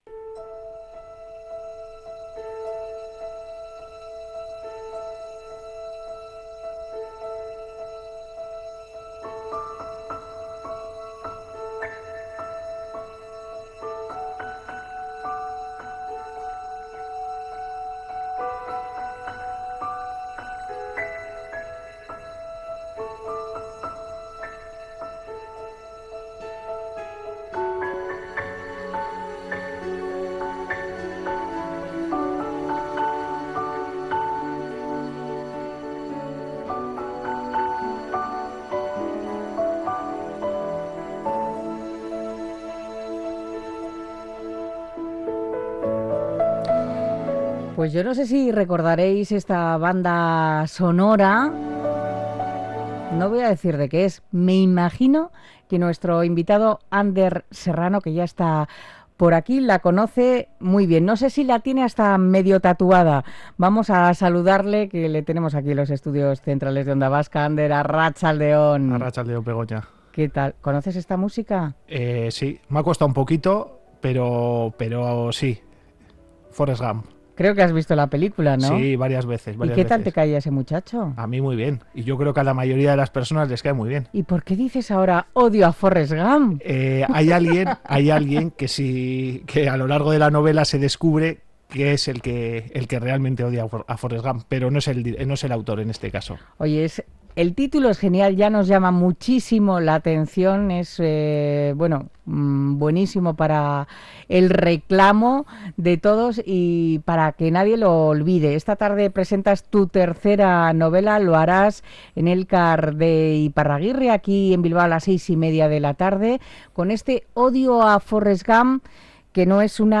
Risas aseguradas en "Odio a Forrest Gump"